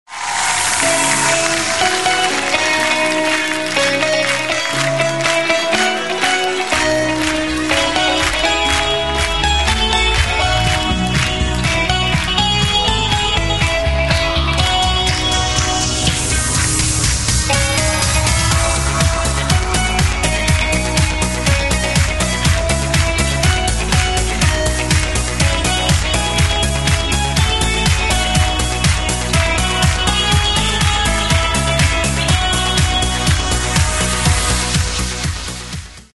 реалтоны pop